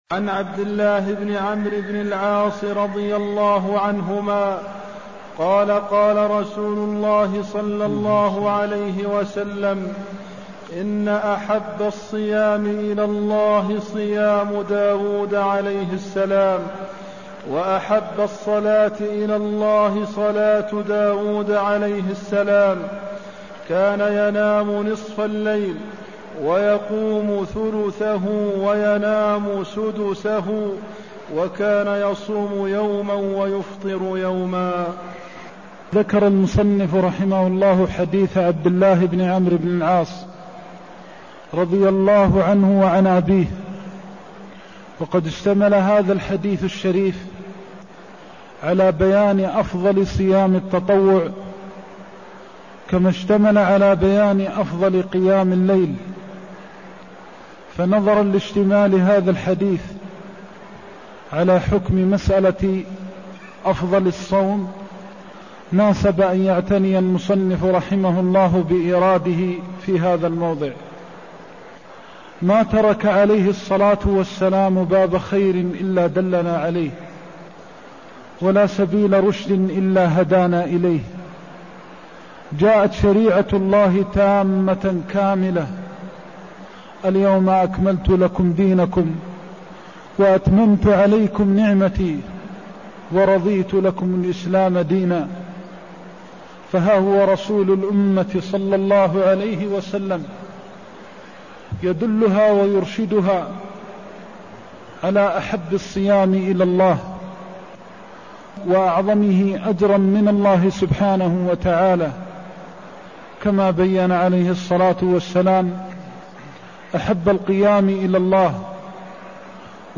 المكان: المسجد النبوي الشيخ: فضيلة الشيخ د. محمد بن محمد المختار فضيلة الشيخ د. محمد بن محمد المختار أحب الصيام إلى الله صيام داود (189)